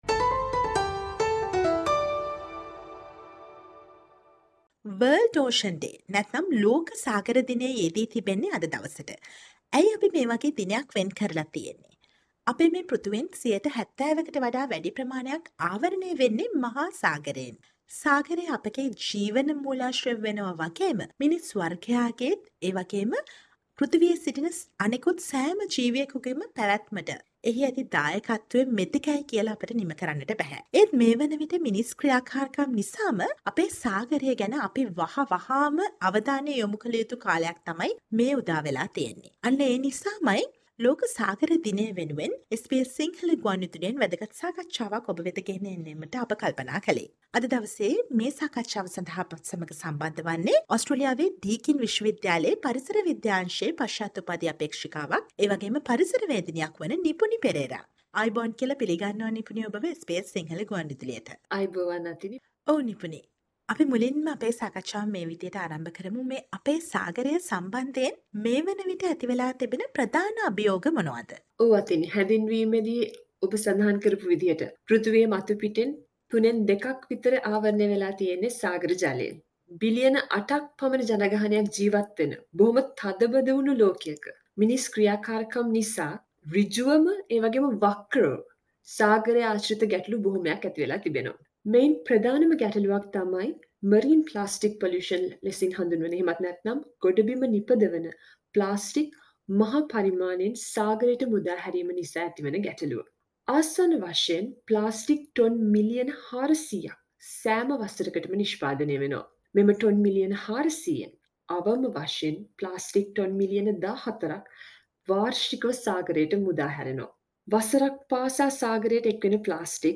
A Discussion on what is the message of World Ocean Day 2023 theme: Planet Ocean, tides are changing and on what you can do to ensure a healthier and more sustainable oceans of our planet.